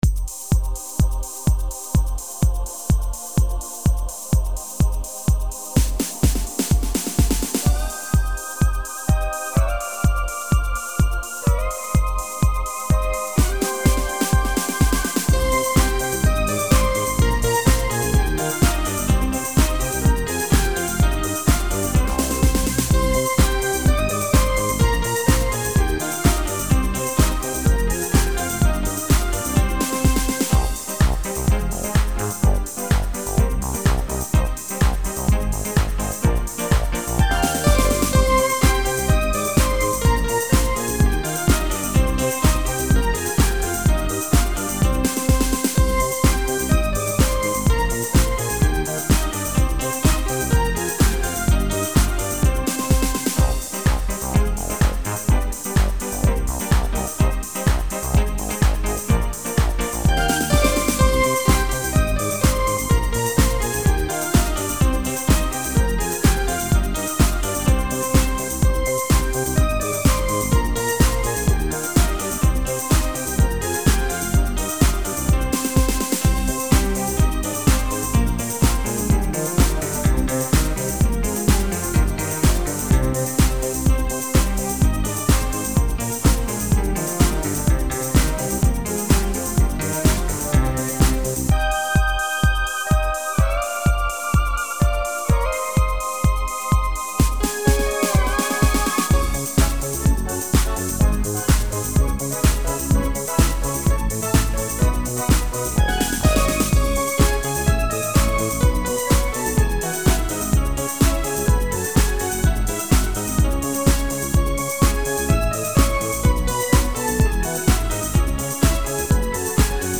минусовка версия 217235